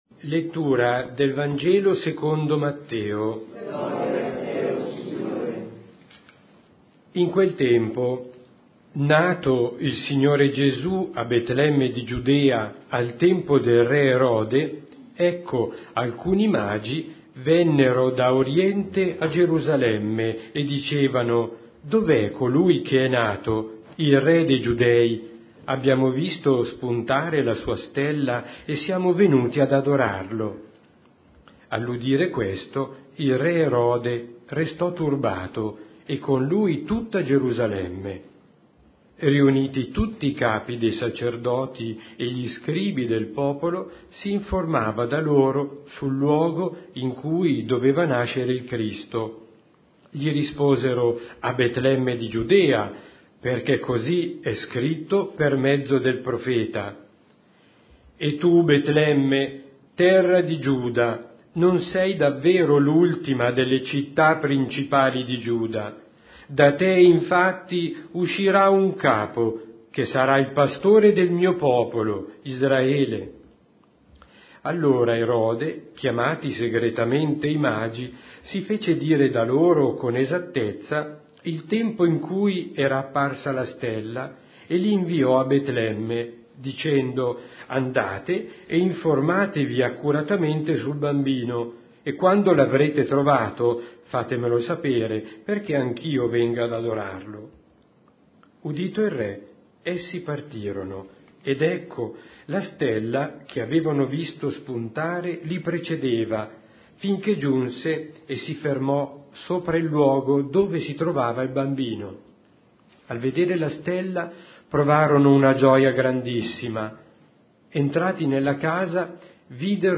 Omelia della Santa Messa del giorno Episodio del 06/01/20 21:00 a cura del mons.